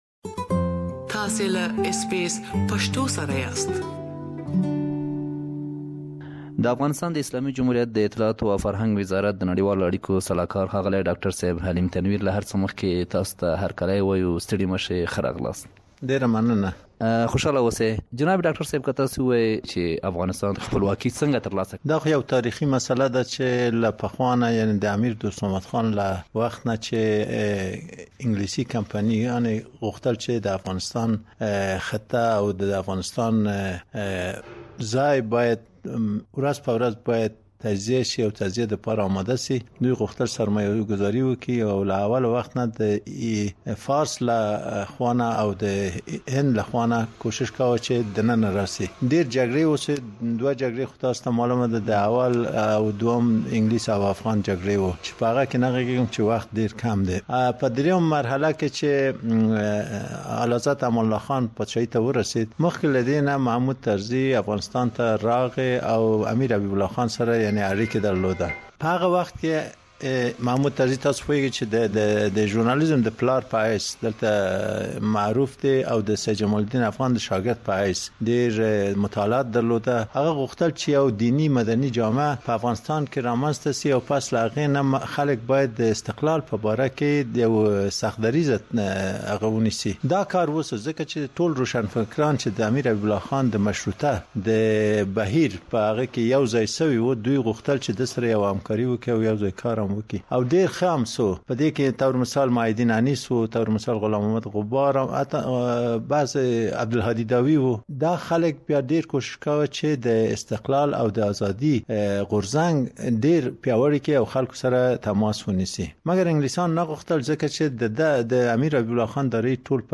تاسې له معلوماتو دا ډکه مرکه دلته اوريدلی شئ.